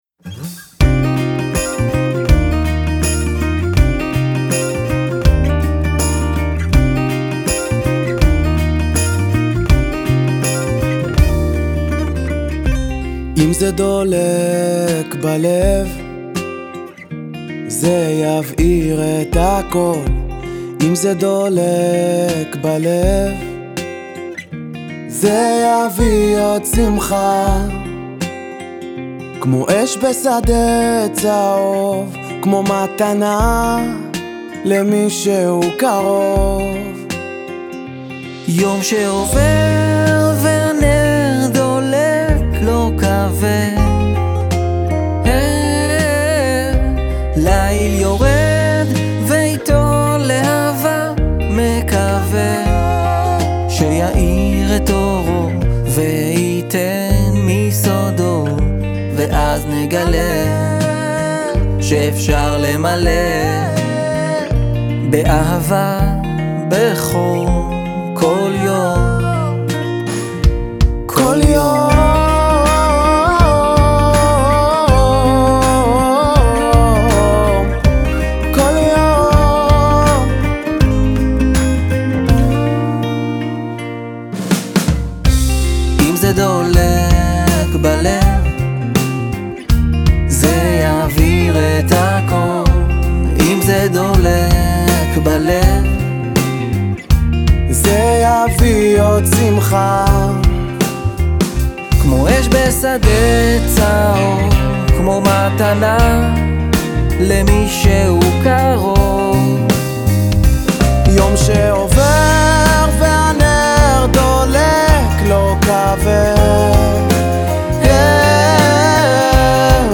דואט